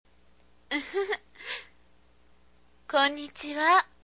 １０代後半/女性
サンプルボイス